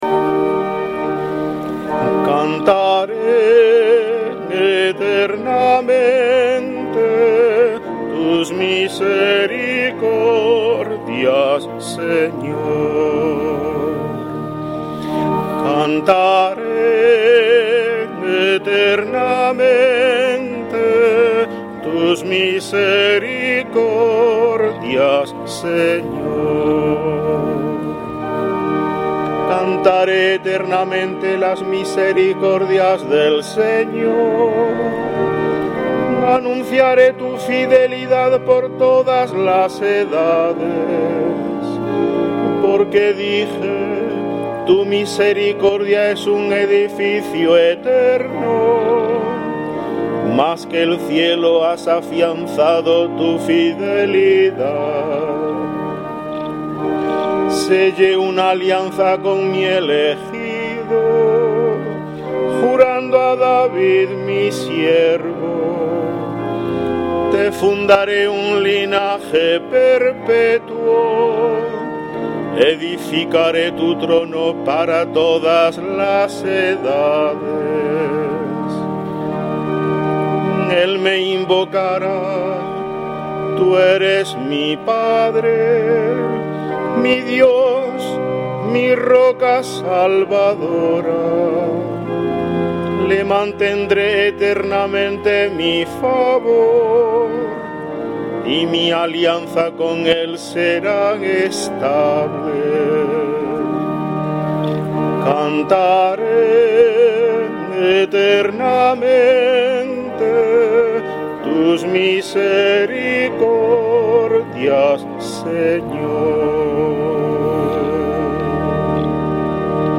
Salmo Responsorial 88/ 2-5; 27; 28